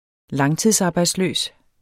Udtale [ ˈlɑŋtiðsˌɑːbɑjdsˌløˀs ]